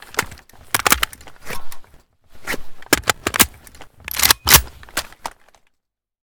an94_reload_empty.ogg